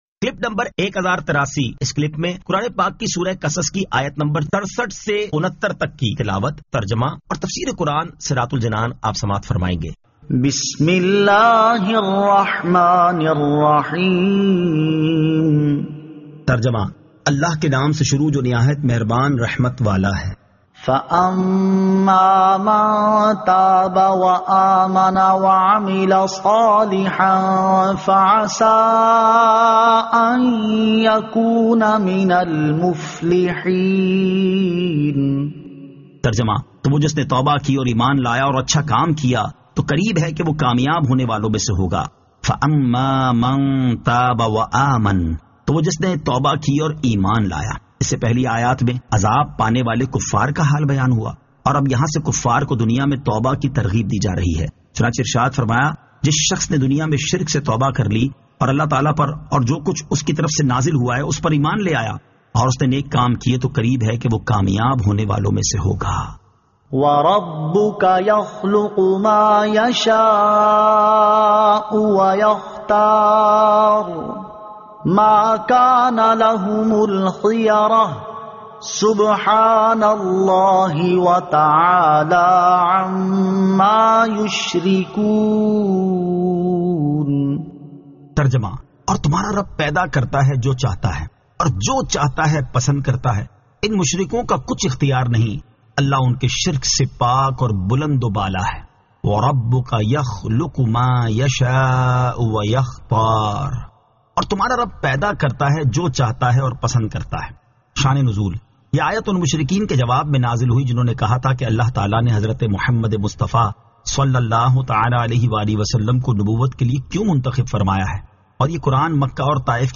Surah Al-Qasas 67 To 69 Tilawat , Tarjama , Tafseer